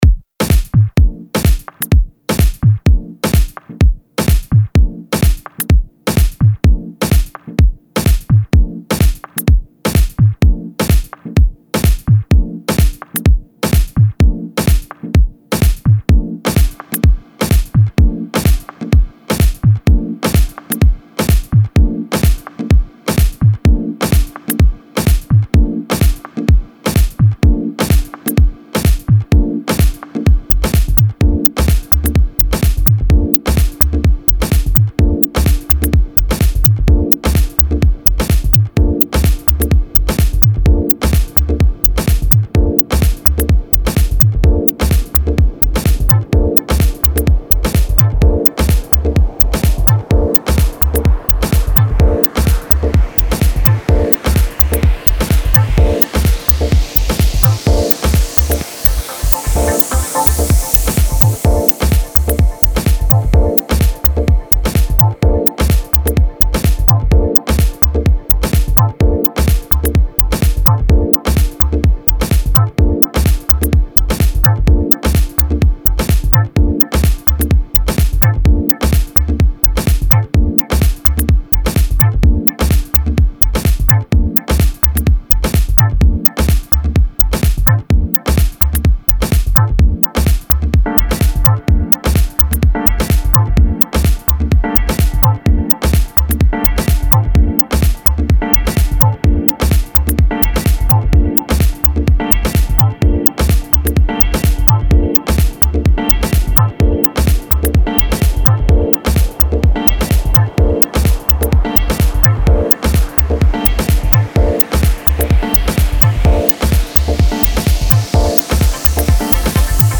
Minimal, Electronic, Jazz, Tech, Vintage, Oldschool - House